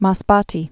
(mäs-bätē, -tĕ)